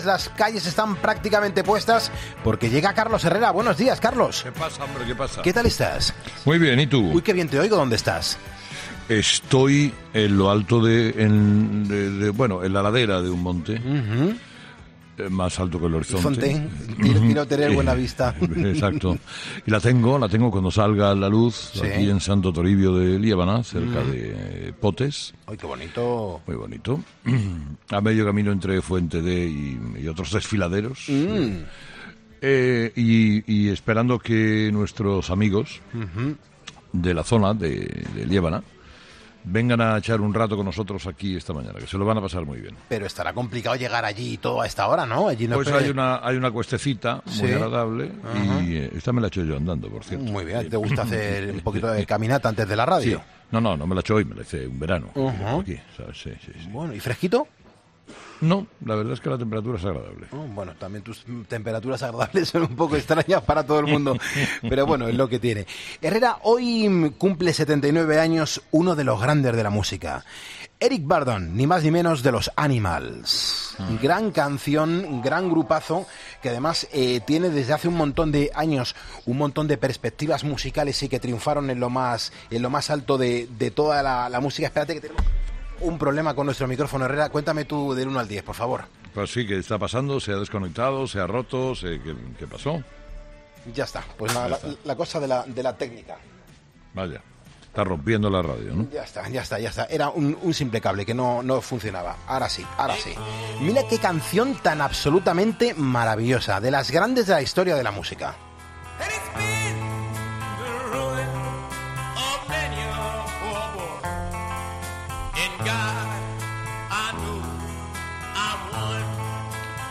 Una fantástica selección musical.